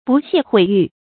不屑毁誉 bù xiè huǐ yù
不屑毁誉发音